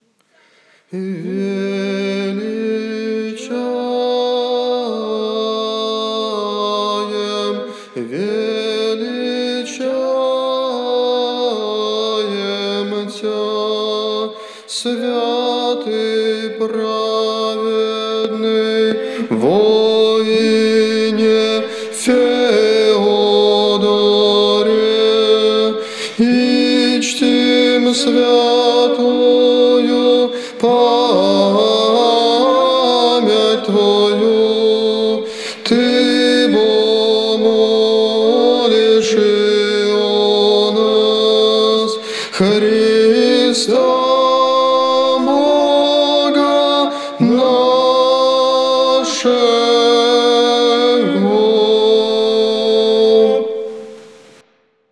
Величание